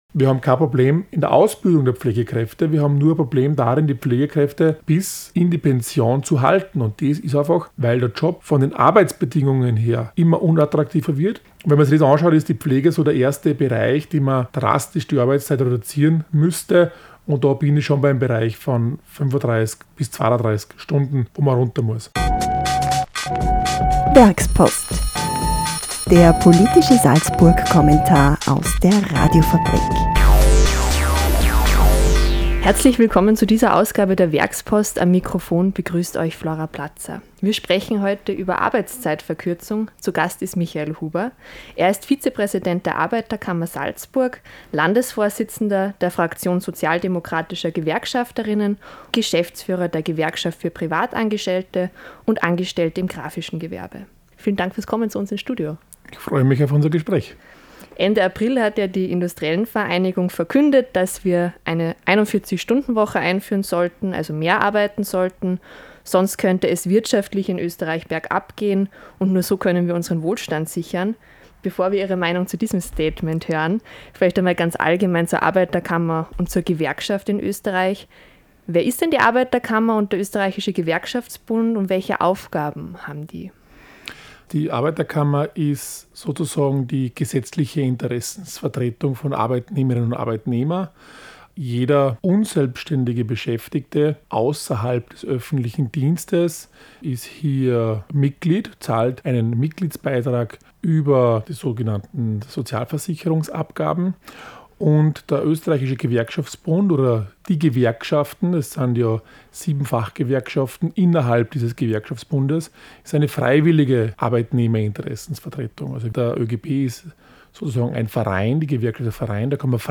Der Werkspodcast vertieft das jeweilige Thema des Werkspost-Kommentars in einem Studiogespräch.